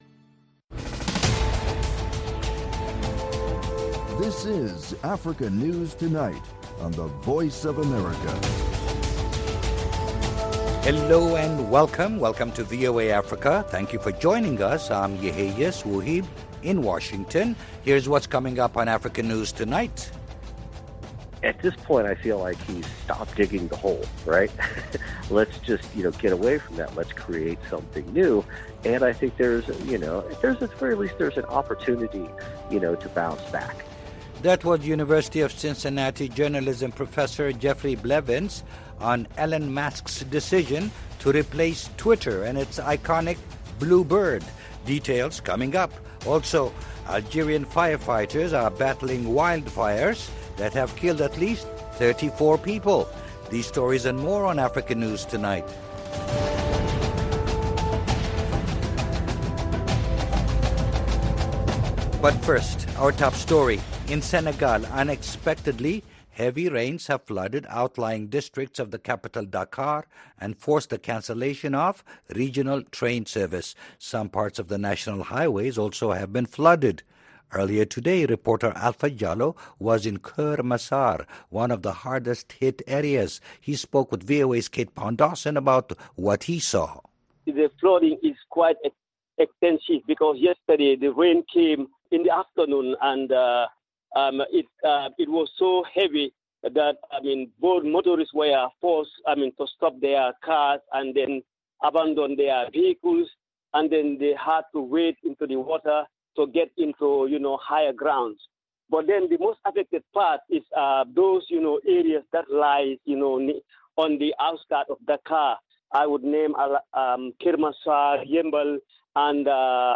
Africa News Tonight is a lively news magazine show featuring VOA correspondent reports, interviews with African officials, opposition leaders, NGOs and human rights activists. News feature stories look at science and technology, environmental issues, humanitarian topics, and the African diaspora.